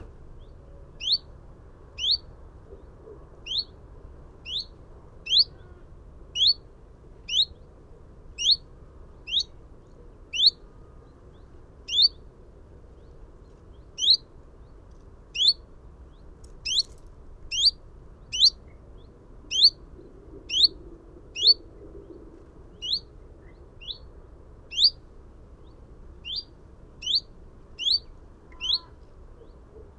pouillot-veloce.mp3